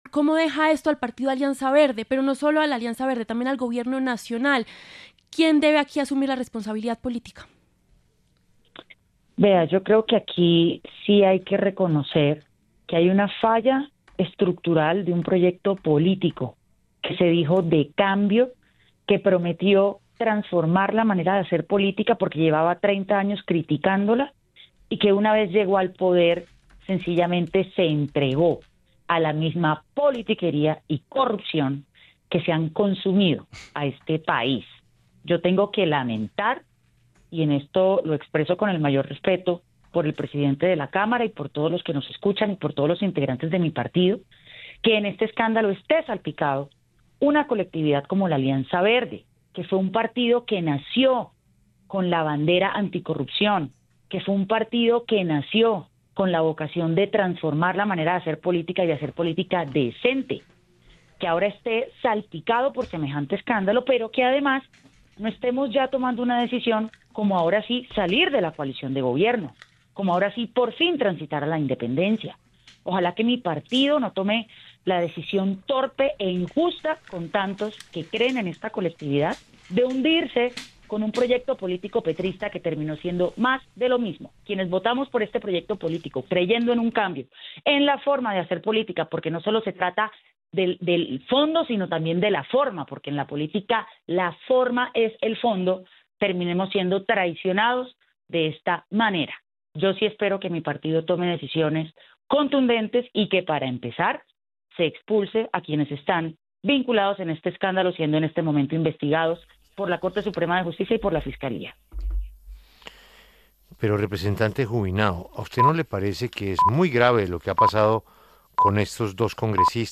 Desde el partido Alianza Verde, la representante Catherine ‘Cathy’ Juvinao reaccionó en La W a la captura de Iván Name por su presunta participación en el escándalo de la UNGRD.